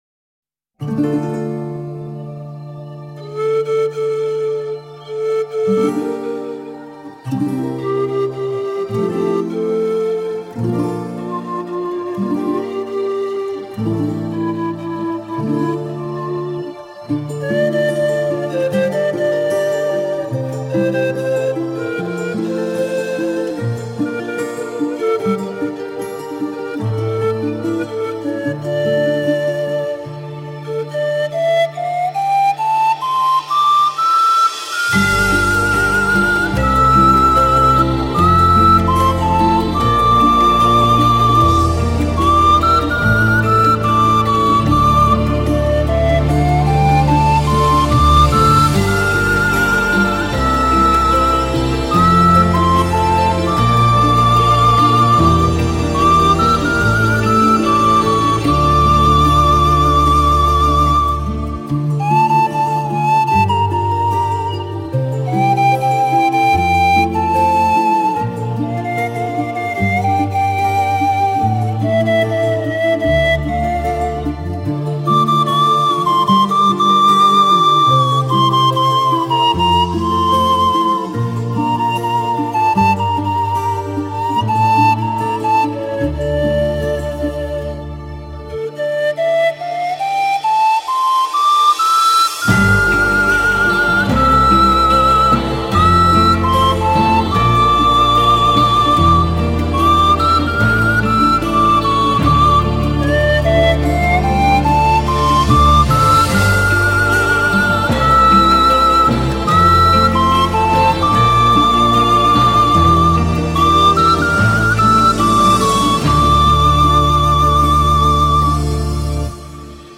пан флейта